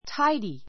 tidy A2 táidi タ イディ 形容詞 比較級 tidier táidiə r タ イディア 最上級 tidiest táidiist タ イディエ スト よく整頓 せいとん された, 片付いた, きちんとした a tidy room a tidy room きちんとした部屋 She is always neat and tidy.